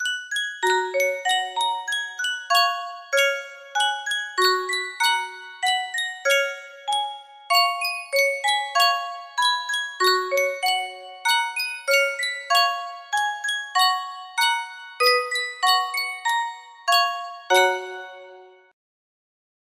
Sankyo Spieluhr - An der Saale hellem Strande UY music box melody
Full range 60